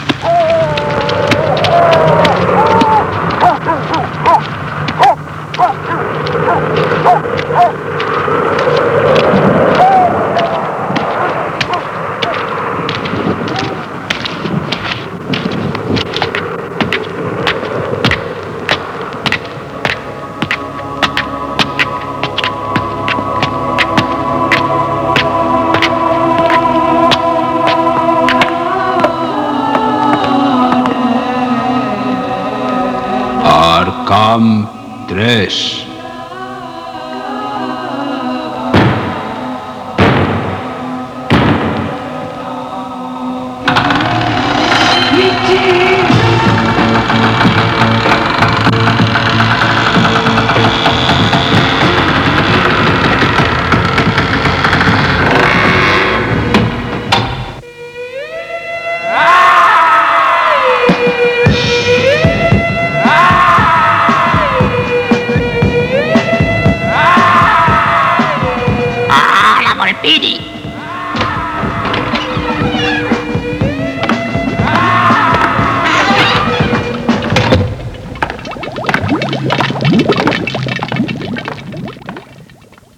Careta del programa.